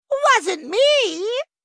pen-flinger-hearthstone-card-sounds-quotes-scholomance-academy-copy-mp3cut.mp3